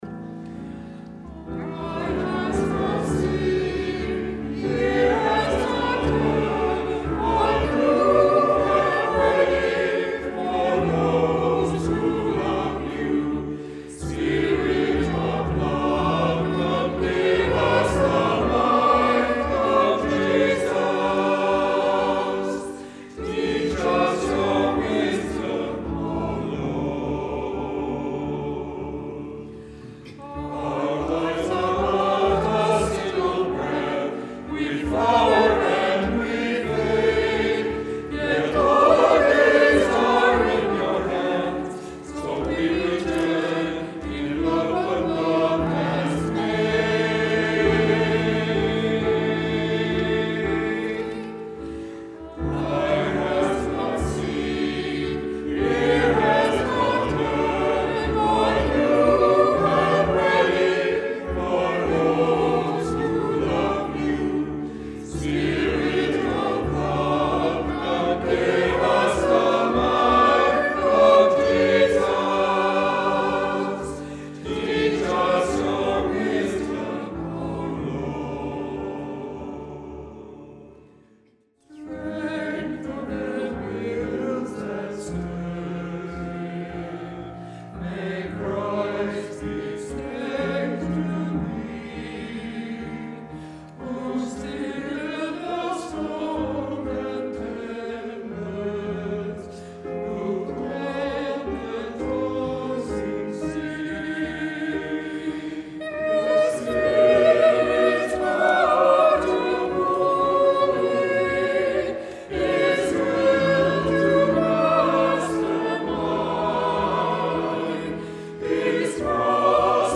Twenty-fourth Sunday after Pentecost 2018
Choir music Nov 4th.mp3